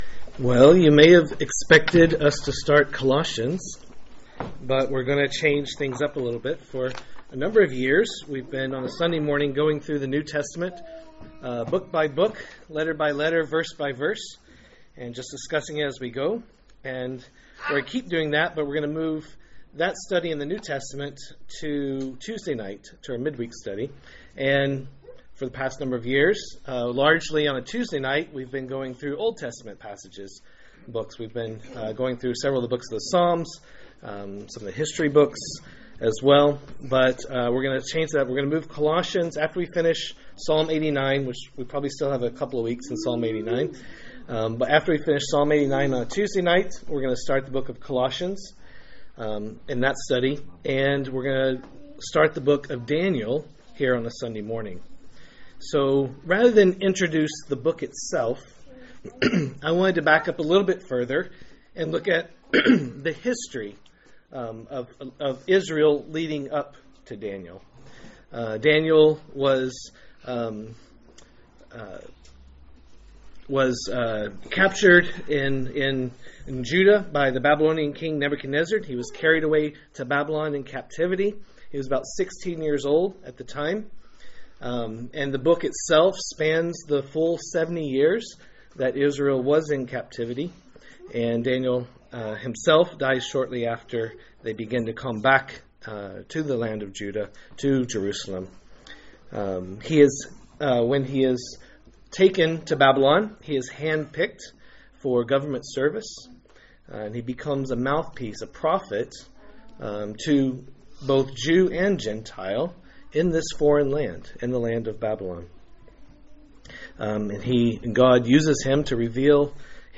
A message from the series "Daniel."